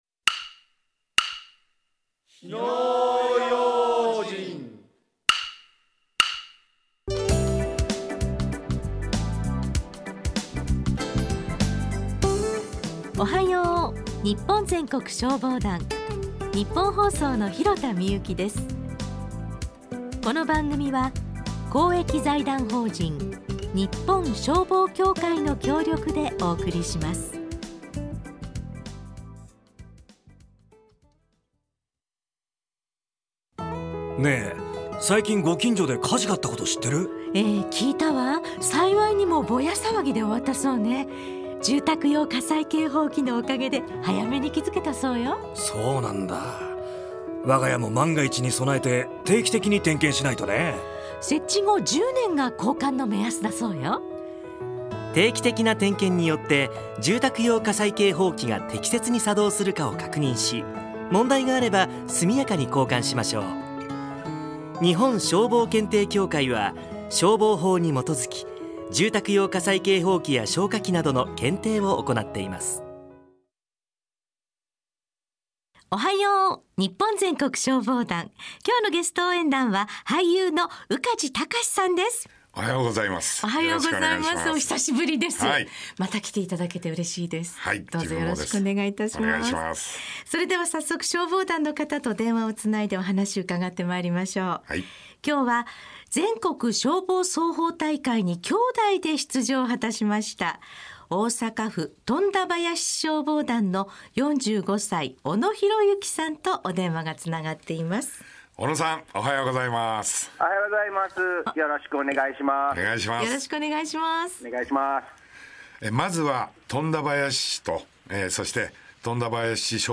ラジオ放送
令和6年3月のゲストパーソナリティ